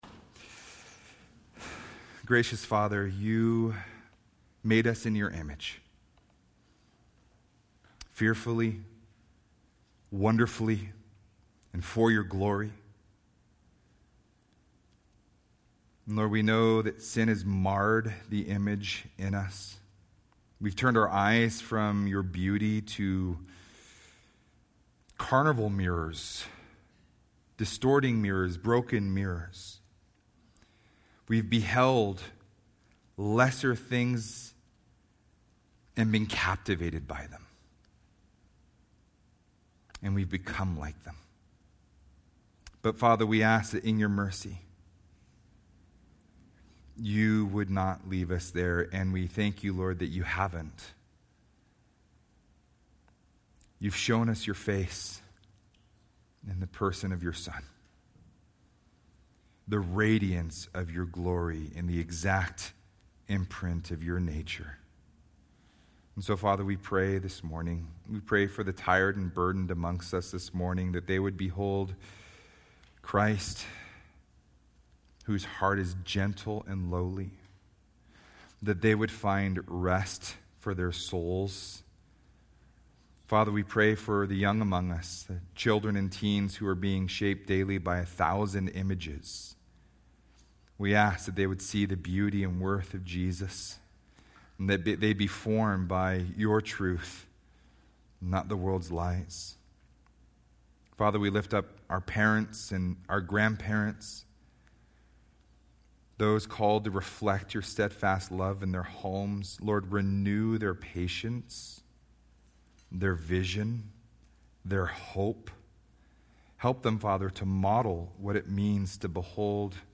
Genesis 1-11 " Sermon Notes Facebook Tweet Link Share Link Send Email